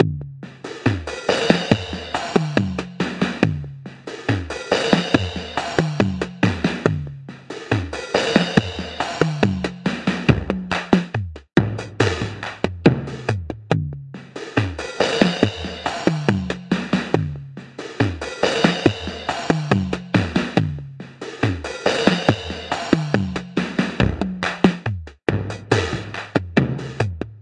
攻击循环2 " 75 bpm 原声破管攻击循环7
各种效果都是相当失真。
Tag: 4 75bpm drumloop